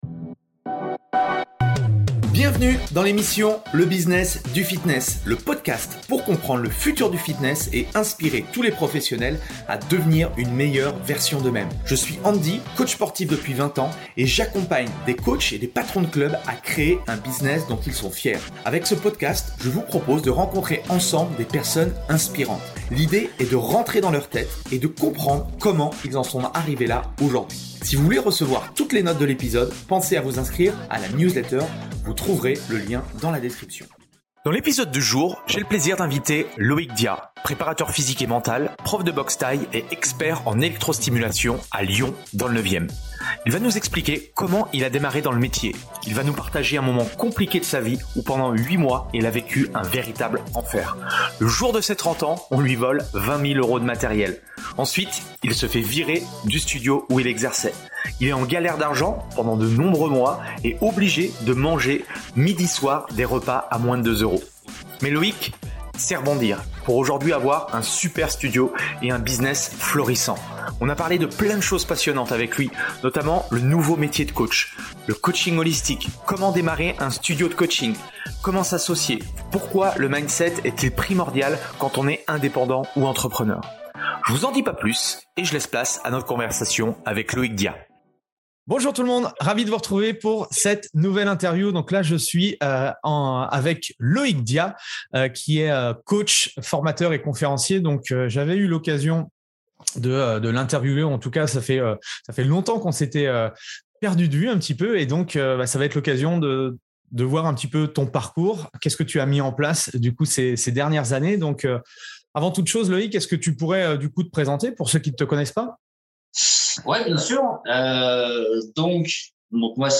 Une belle conversation sans langue de bois dans laquelle je balance pas mal de vérités et j’explique les nombreuses galères qui me sont arrivées avec les prises risques que j’ai pris à ce moment là.